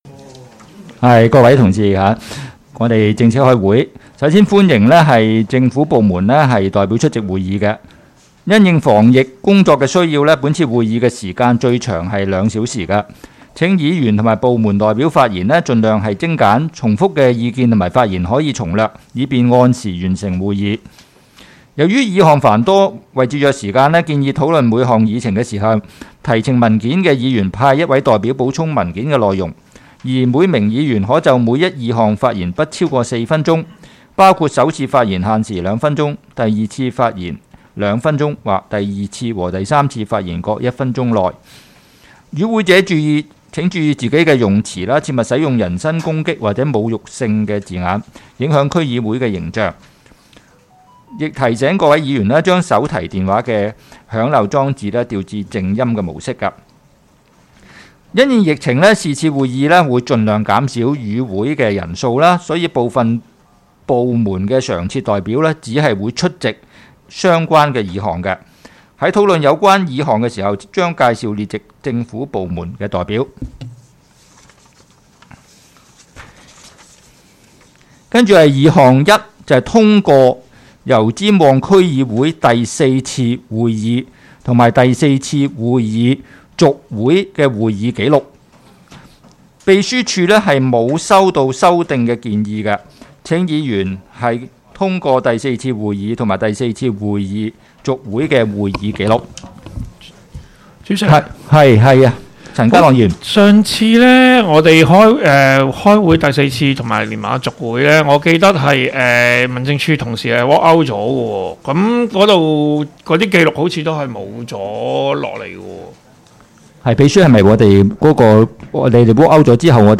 油尖旺区议会 - 区议会大会的录音记录
区议会大会的录音记录